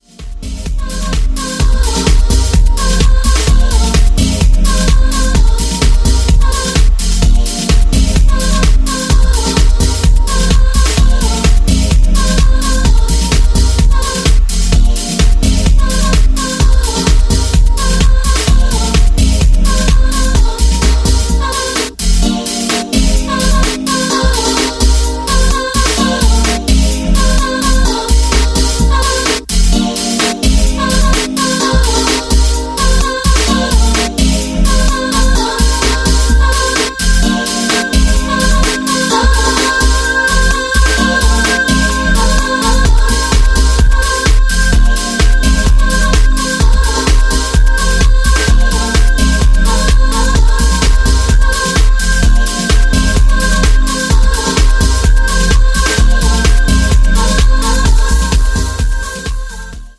初期90’s レイヴの気分なヴォーカルショットがキラーな”B1”。